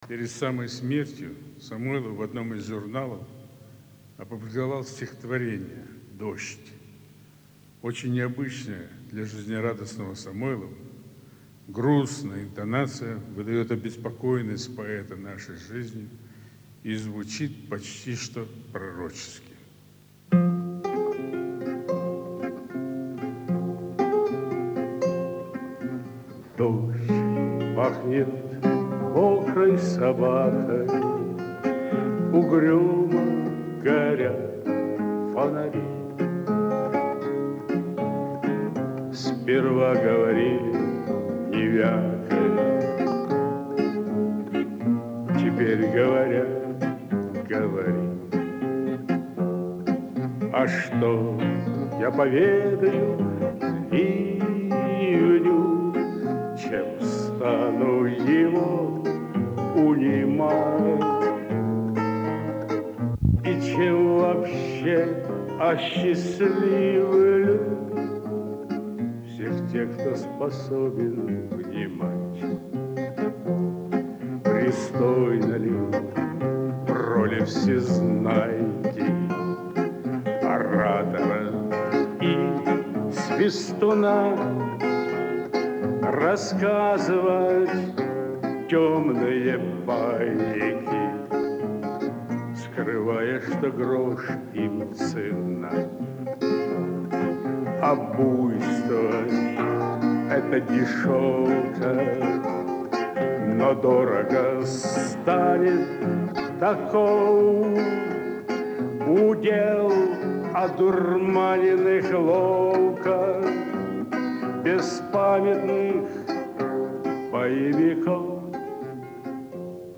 в Олимпийской деревне (30.09.1993):